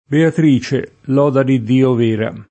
lode [ l 0 de ]